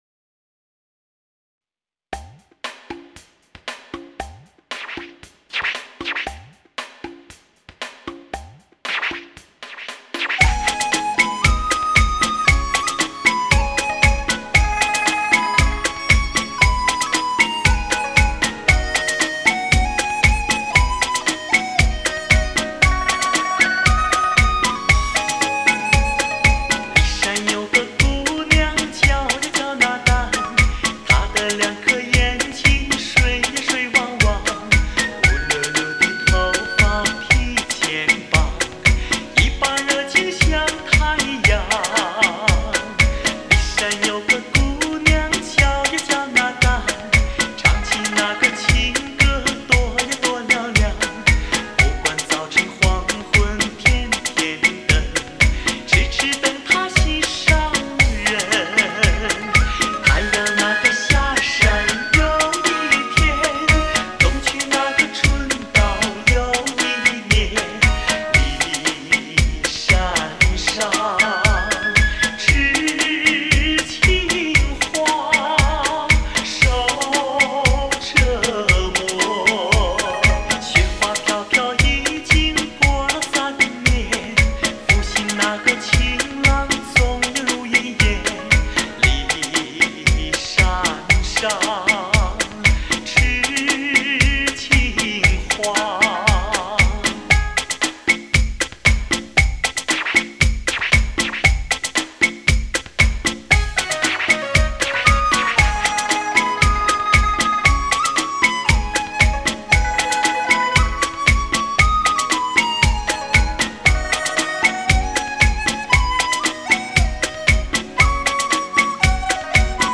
本专辑从新制作，鼓点全新制作。
清晰悦耳的声音。